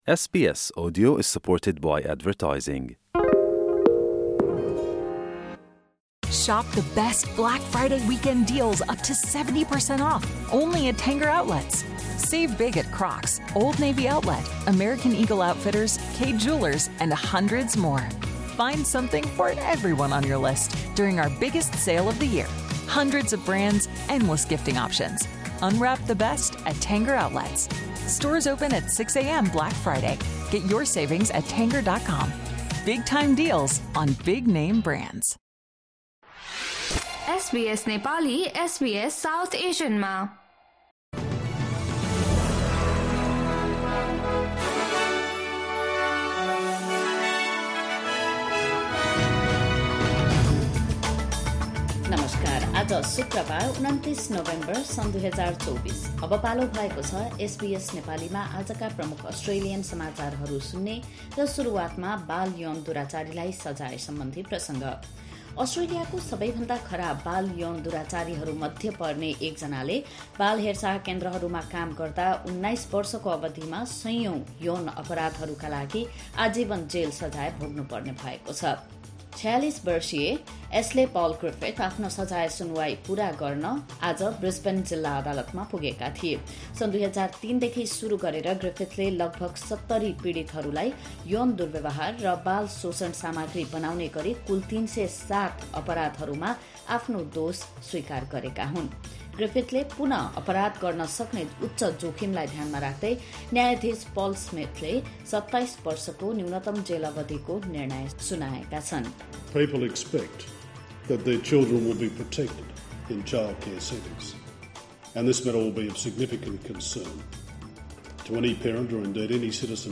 आजका प्रमुख अस्ट्रेलियन समाचार छोटकरीमा सुन्नुहोस्।